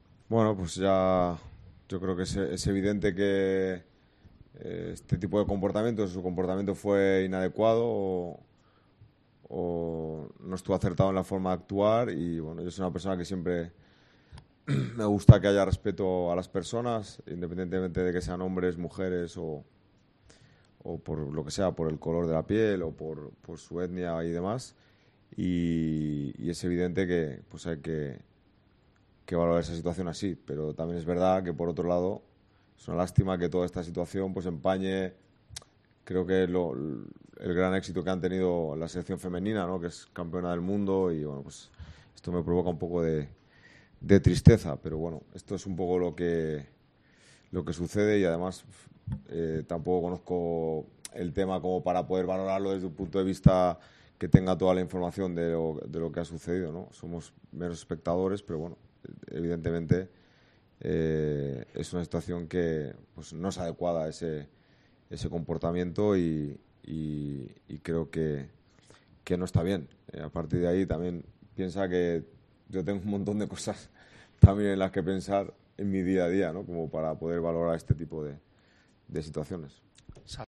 El entrenador del Valencia, Rubén Baraja, señaló en la rueda de prensa previa al partido en Mestalla frente a Osasuna que el técnico Joseba Arrasate ha imprimido su sello personal al conjunto navarro, al que calificó como un equipo "muy competitivo e intenso".